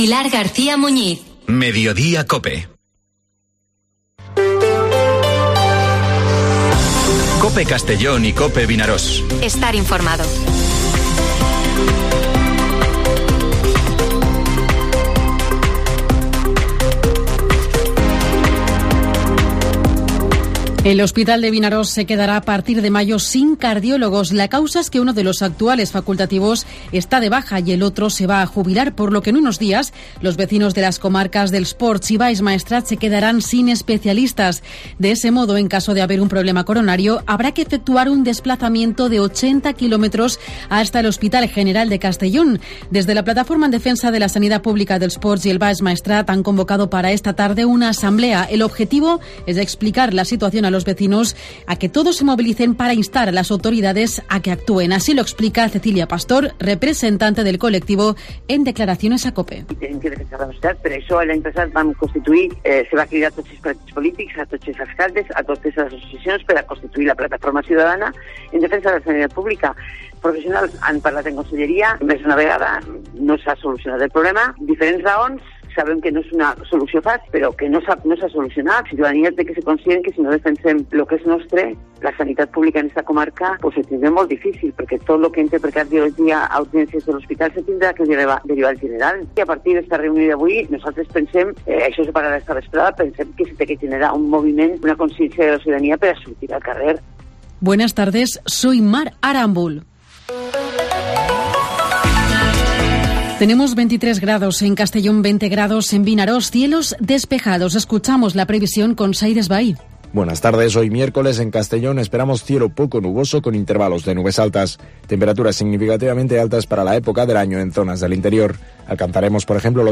Informativo Mediodía COPE en Castellón (26/04/2023)